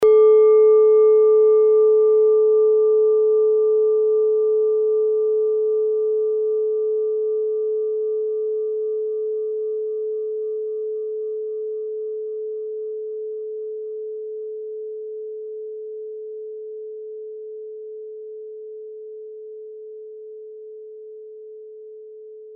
Klangschale Nepal Nr.14
Klangschale-Durchmesser: 14,1cm
(Ermittelt mit dem Filzklöppel)
klangschale-nepal-14.mp3